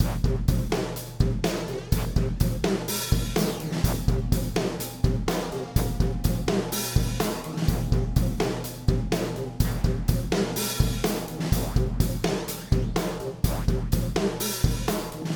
1marching-sounds